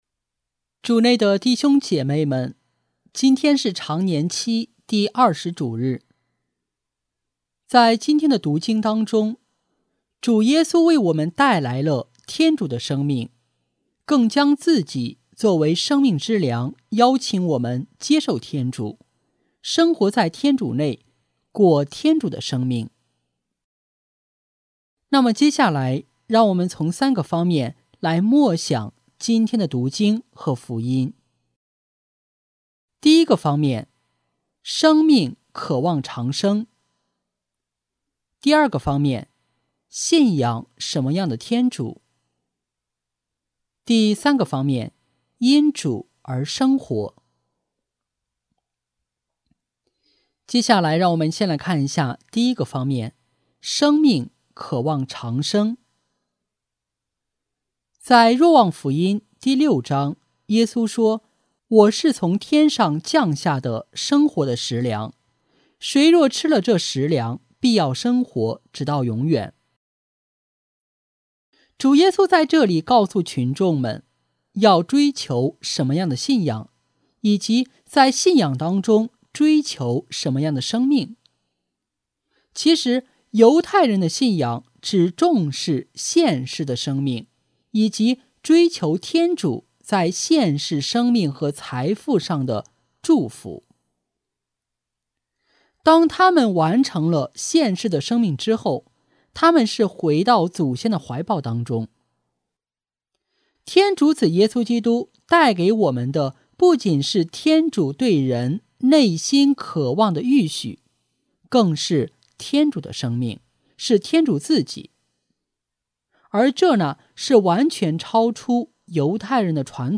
【主日证道】| 追求永恒的生命（乙-常年期第20主日）